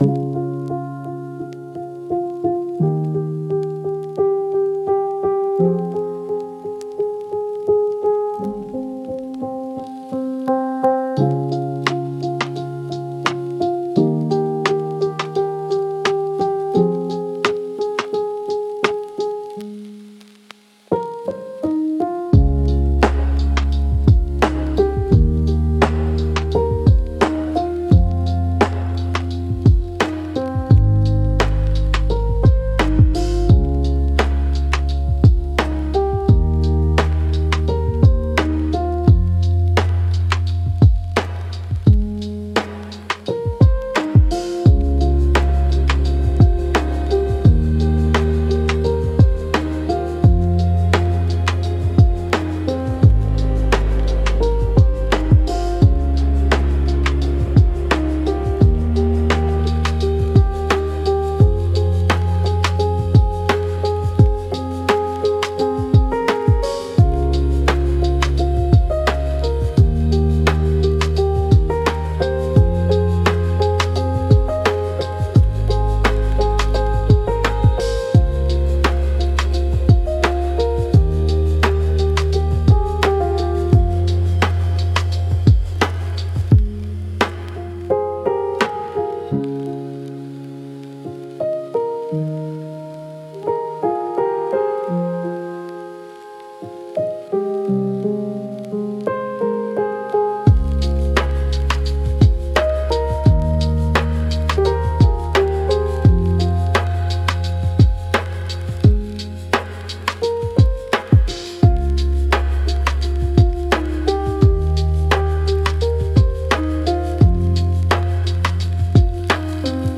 おしゃれ